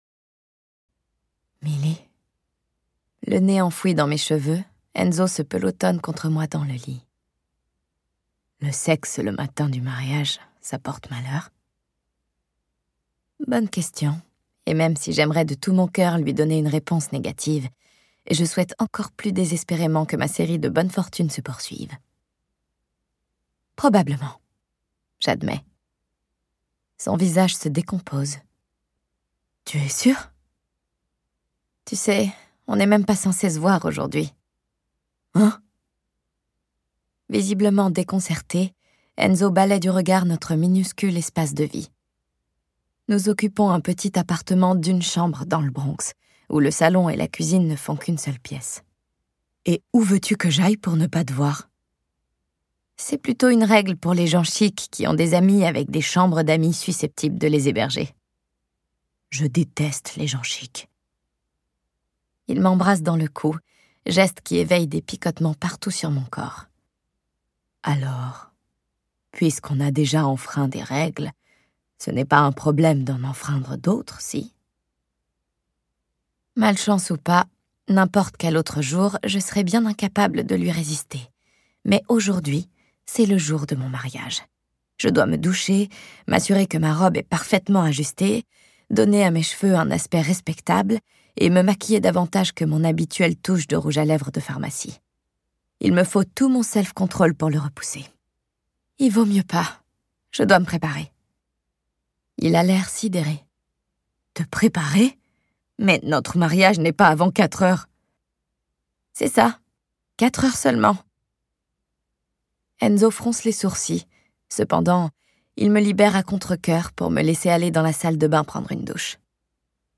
La noce sera célébrée par un trio de comédiens pour